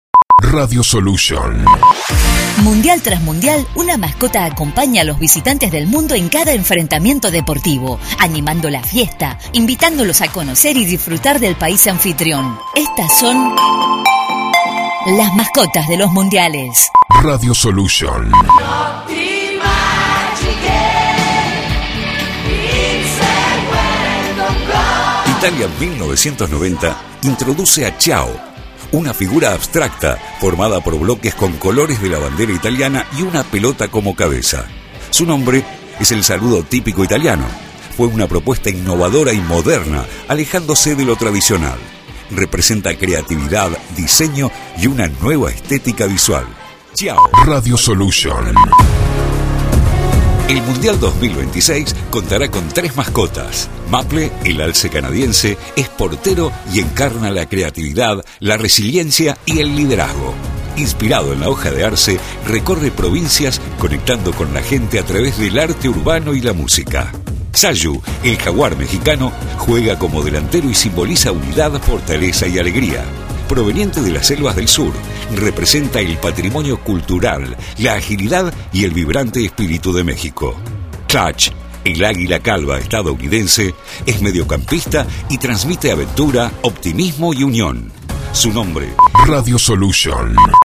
Con música y Efectos
Producción confeccionada a 1 voz